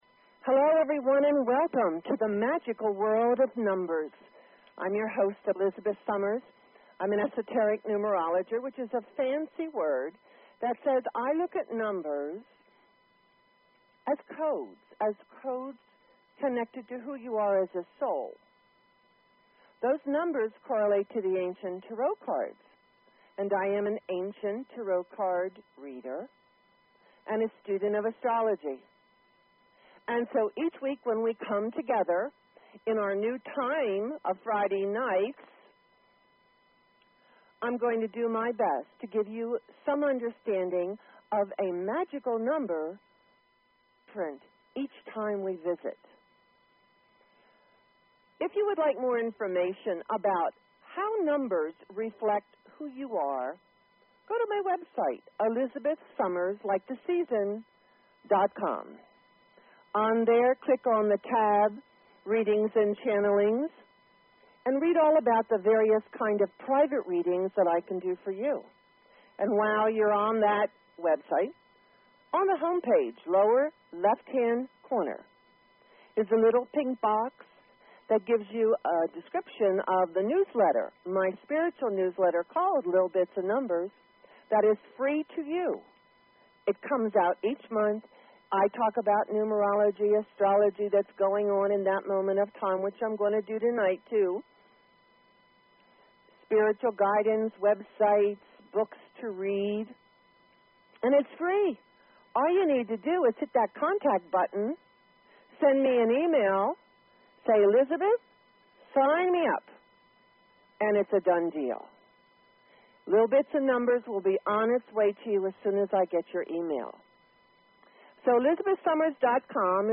Talk Show Episode, Audio Podcast, Magical_World_of_Numbers and Courtesy of BBS Radio on , show guests , about , categorized as